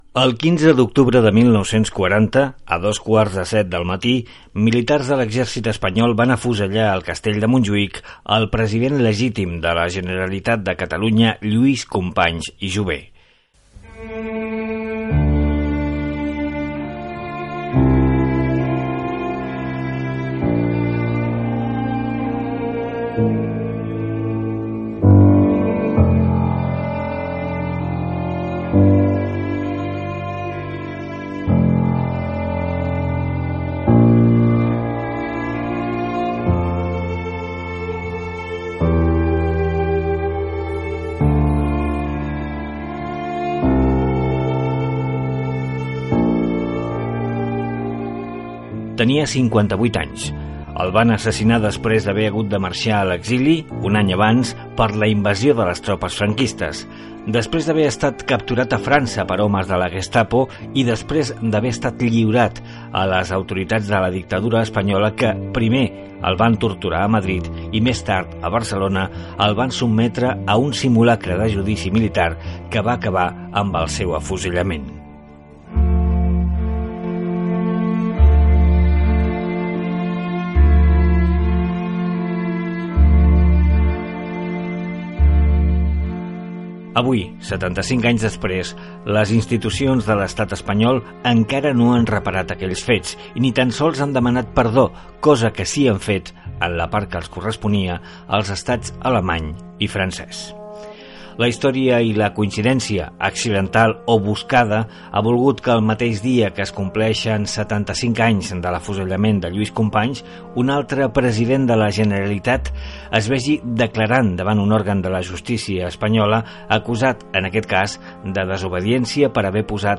Programa documental realitzat amb motiu del 75è aniversari de l’afusellament del president de la Generalitat de Catalunya Lluís Companys. Inclou una declaració institucional de l'Ajuntament de Barcelona sobre el judici i la sentència de mort.